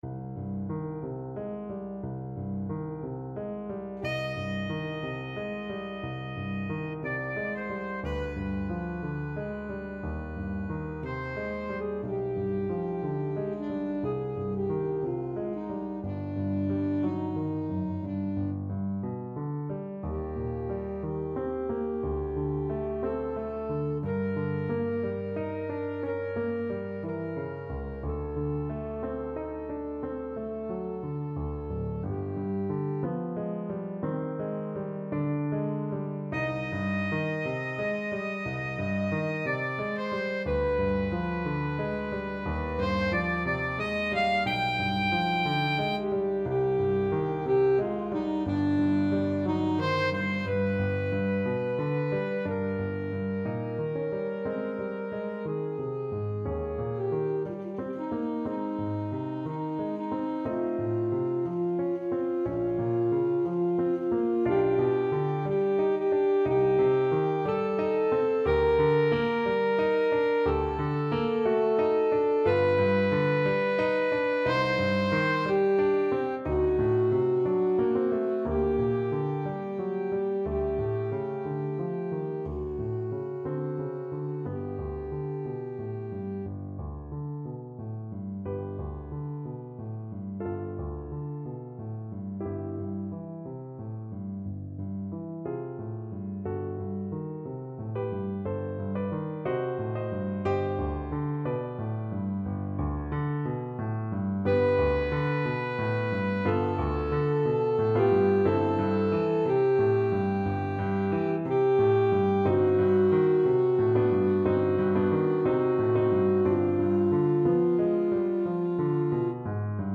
Alto Saxophone
4/4 (View more 4/4 Music)
Andante = 60
Classical (View more Classical Saxophone Music)
Nocturne_Opus_72_No_1_in_E_Minor_ASAX.mp3